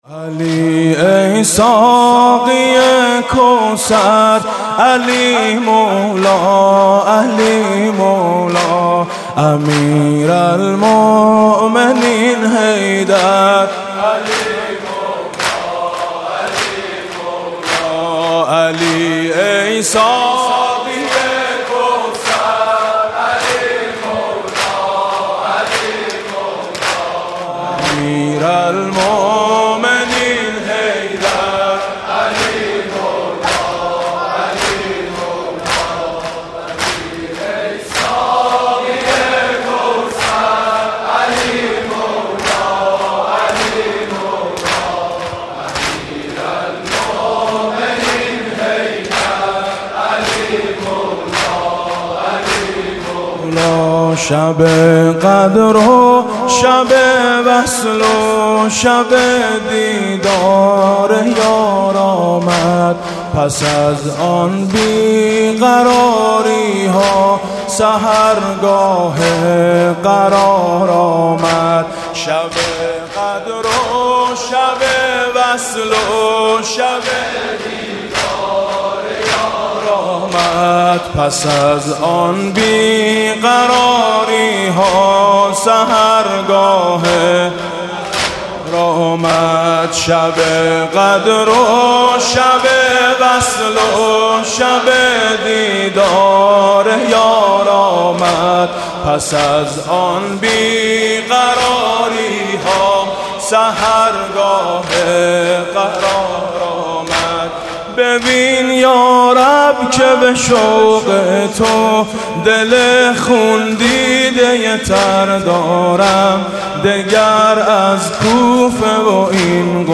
زمینه: نداند قدر مرا این شهر، مرا از کوفه بگیر امشب